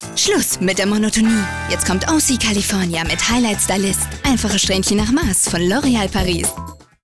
Sprechprobe: Industrie (Muttersprache):
german female voice over artist.